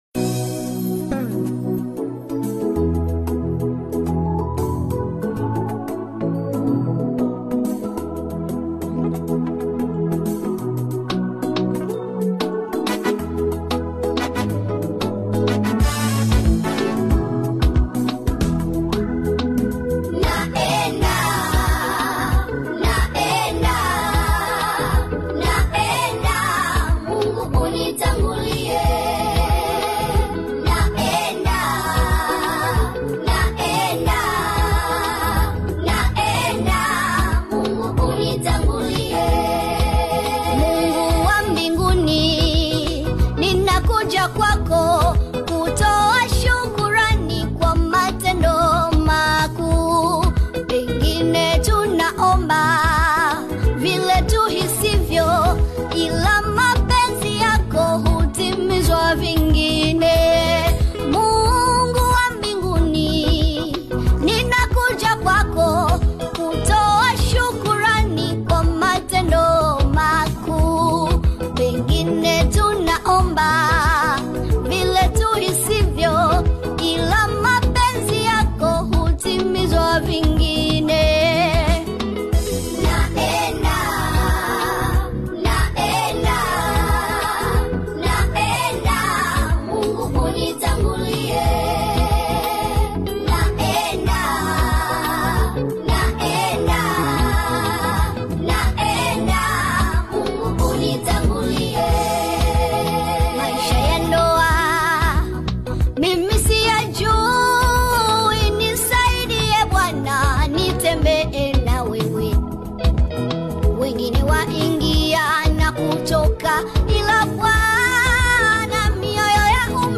AudioGospel
heartfelt gospel single